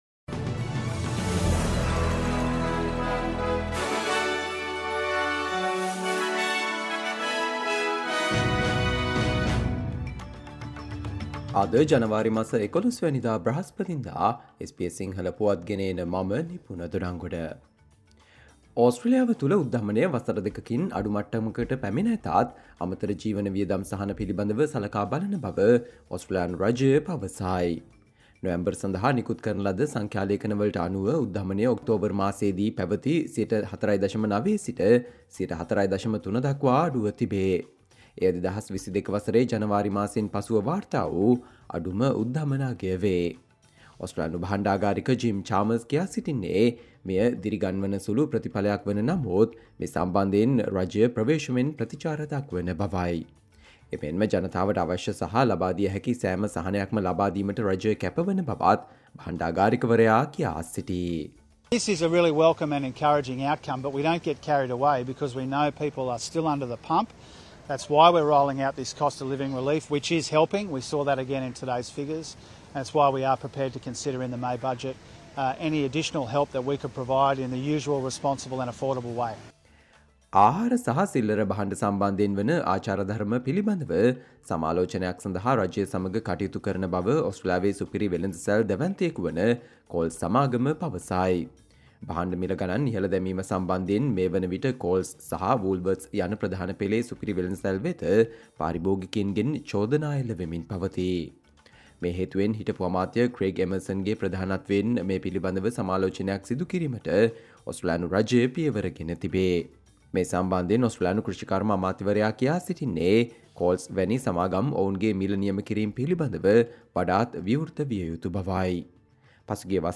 Australia news in Sinhala, foreign and sports news in brief - listen, Thursday 11 January 2024 SBS Sinhala Radio News Flash